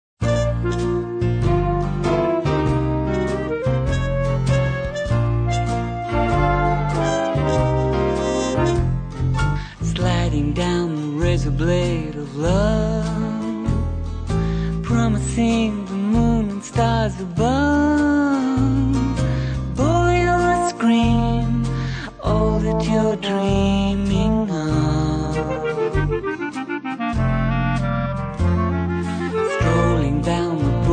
quirky idiosyncratic numbers